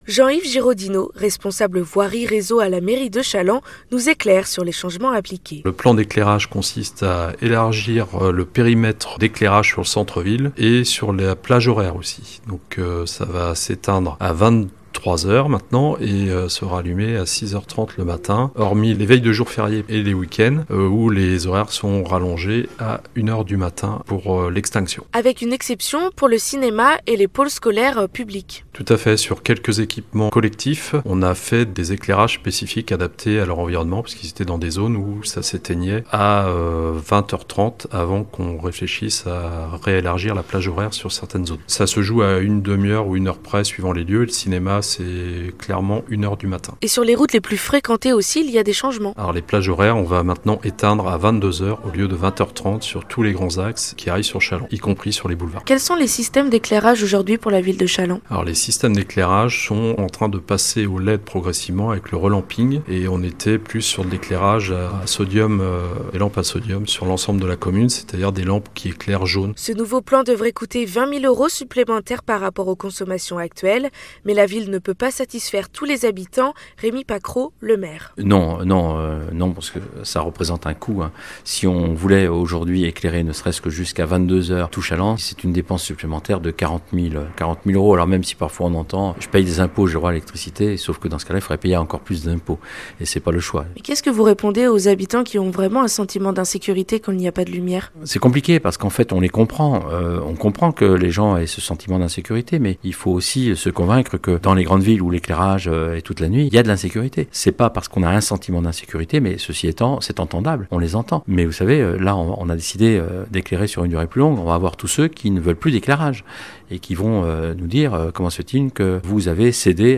Les Reportages de NOV FM